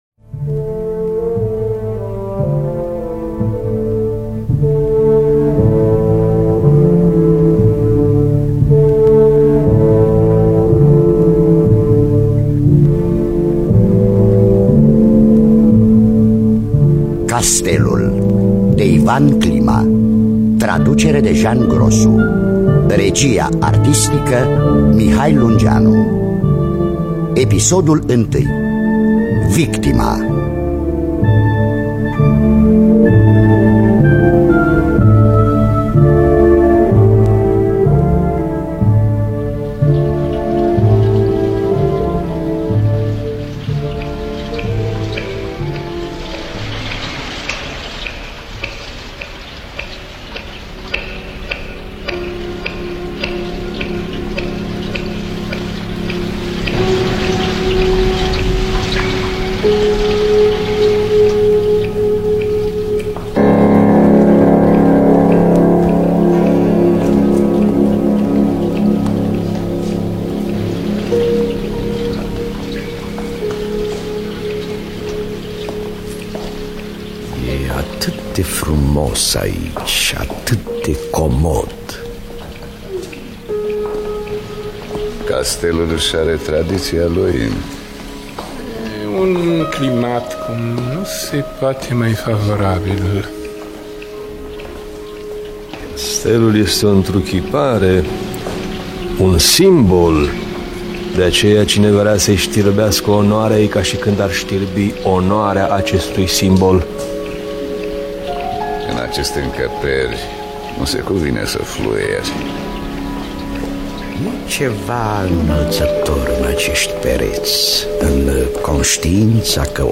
Adaptarea radiofonică